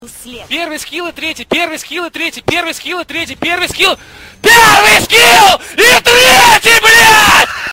pervyi skil i tretii Meme Sound Effect